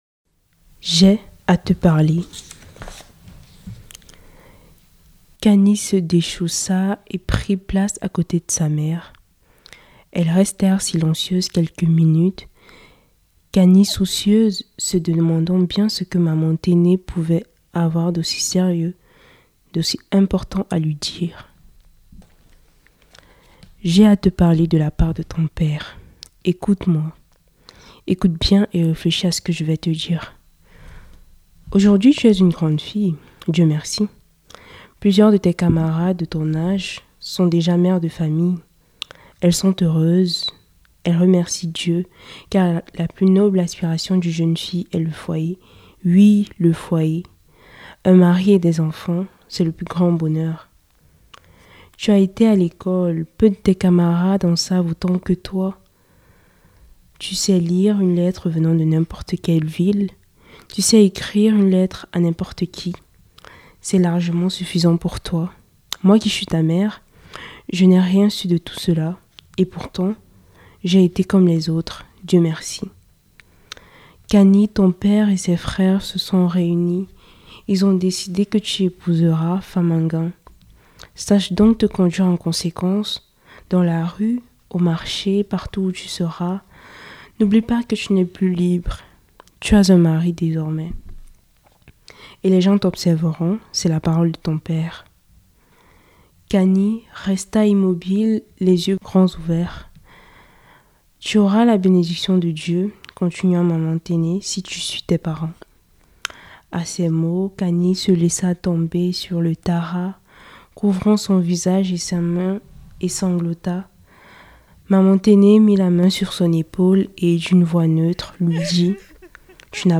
Les ateliers de fictions radiophoniques de Radio Primitive durée : 4:20